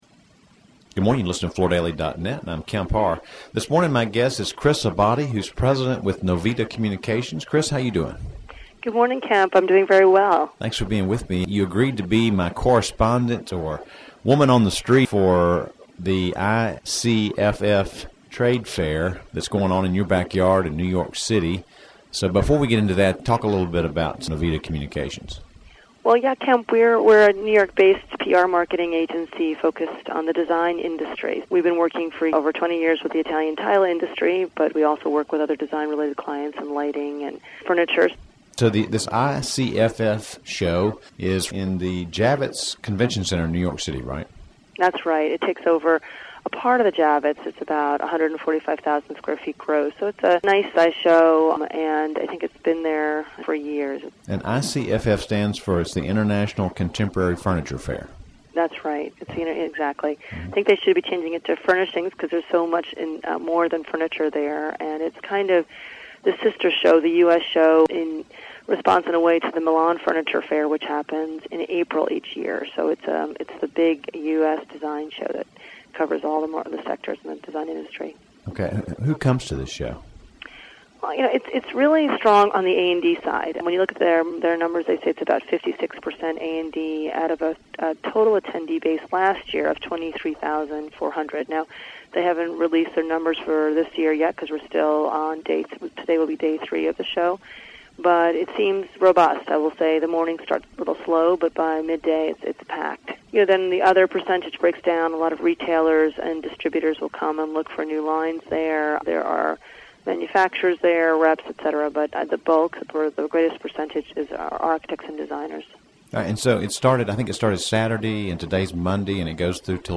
This show is the U.S. version of the Milan Furniture Show held in Italy in April. Listen to the interview to hear about the improved tone and traffic and other highlights.